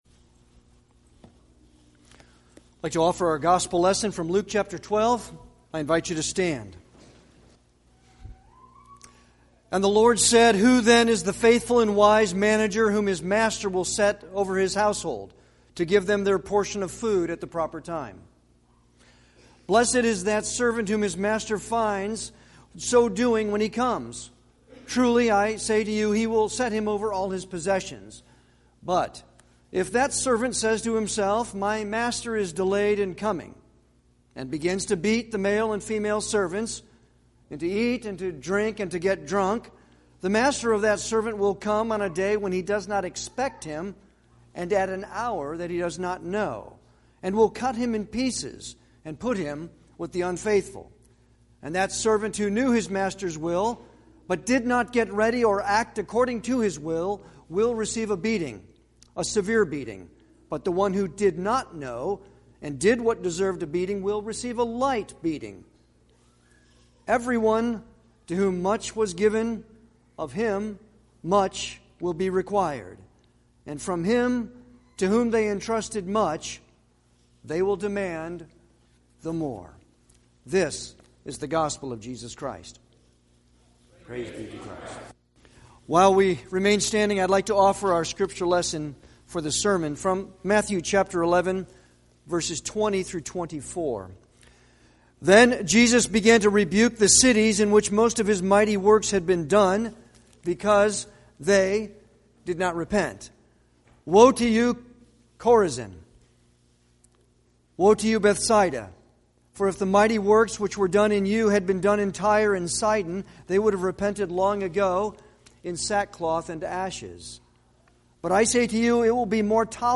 Sermons
Service Type: Sunday worship